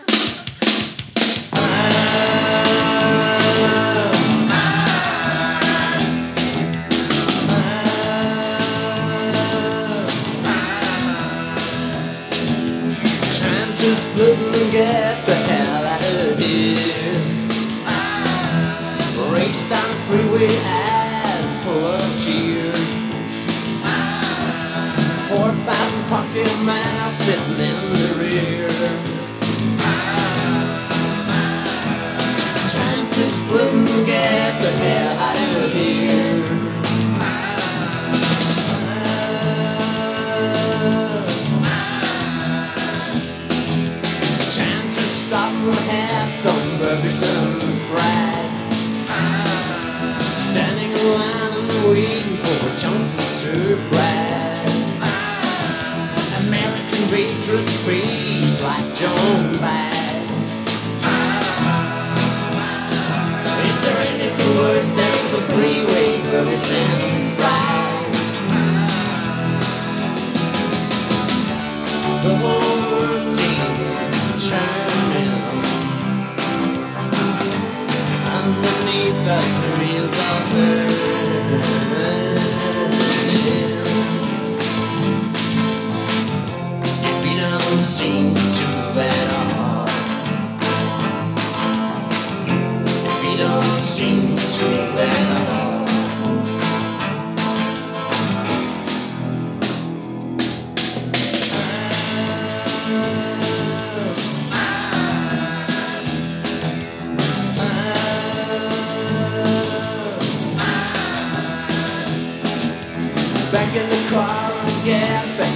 60年代のガレージ/サイケを昇華したサウンドと個性的な唄声でニューウェイヴィーな雰囲気も漂わせる作品です！